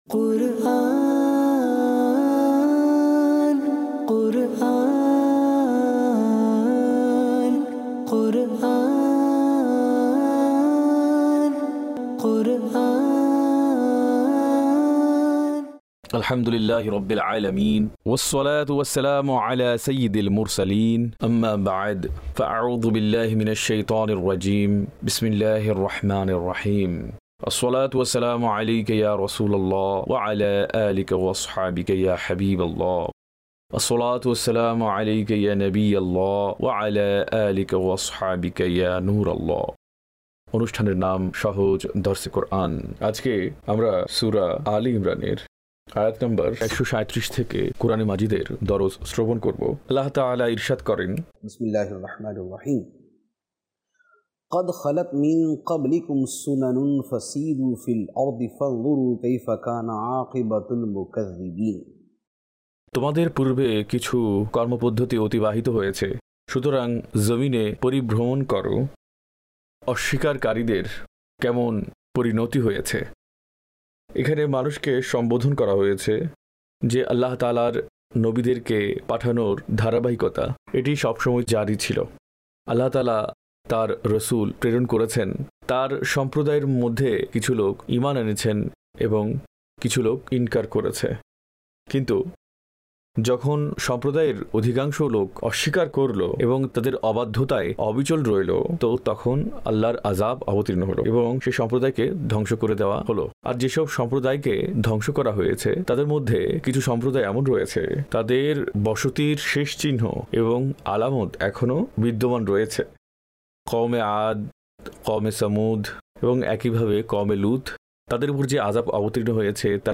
সহজ দরসে কুরআন (বাংলায় ডাবিংকৃত) EP# 27